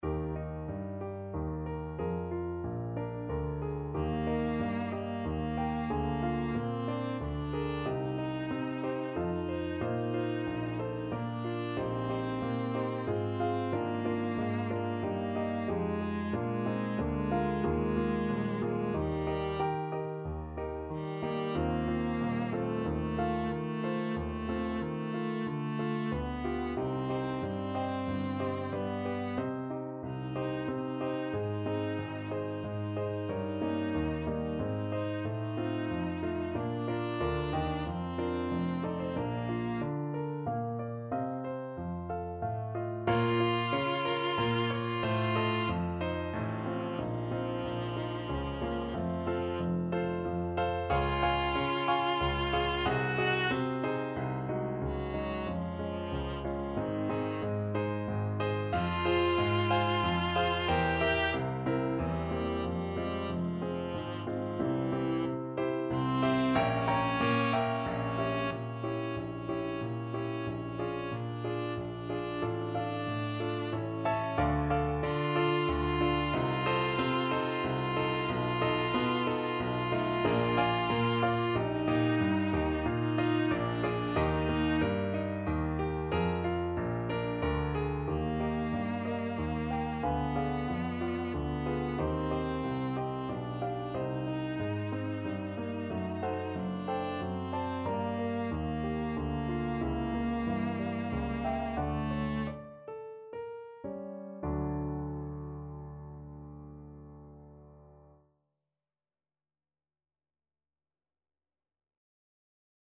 Free Sheet music for Viola
Viola
3/4 (View more 3/4 Music)
~ = 92 Larghetto
Eb major (Sounding Pitch) (View more Eb major Music for Viola )
Eb4-G5
Classical (View more Classical Viola Music)
mozart_requiem_hostias_VLA.mp3